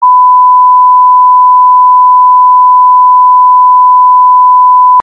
1khz_lr_128k.mp3